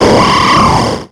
Cri d'Armaldo dans Pokémon X et Y.